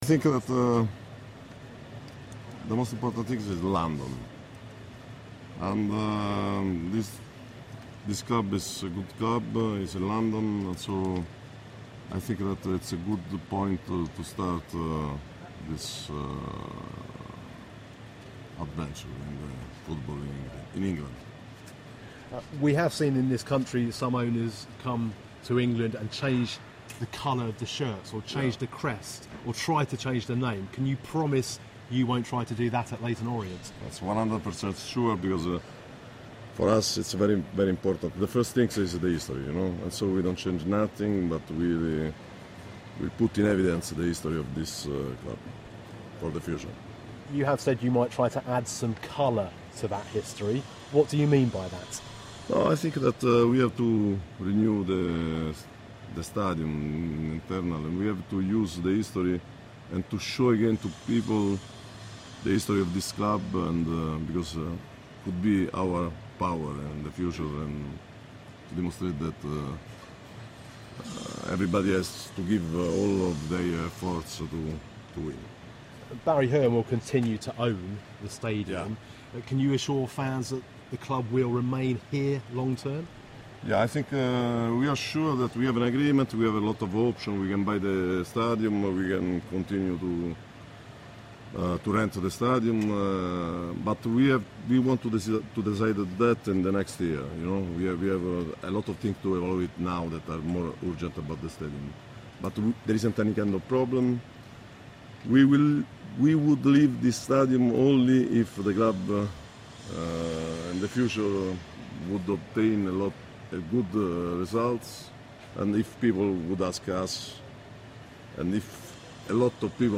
BBC London 94.9 speaks exclusively to Italian businessman